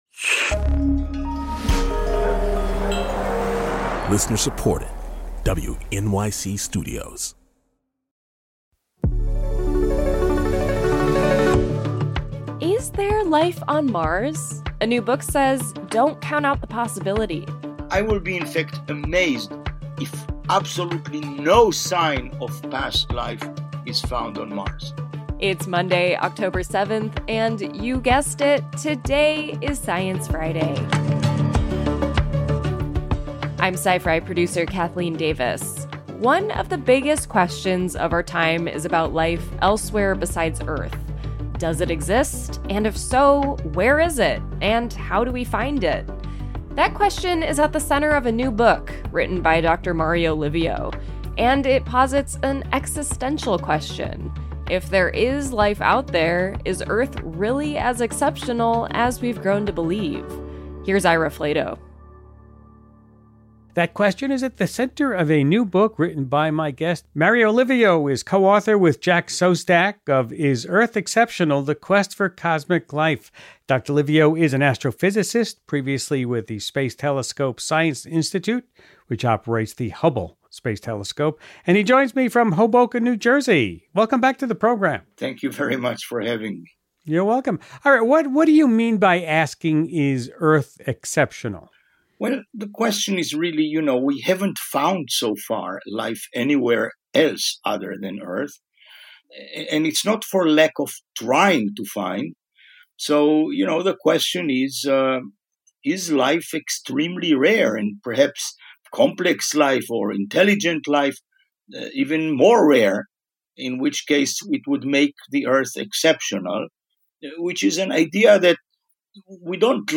Dr. Livio, an astrophysicist previously with the Space Telescope Science Institute, which operates the Hubble Space Telescope, joins Ira to talk about the possibilities of life beyond Earth, and where we would most likely find it.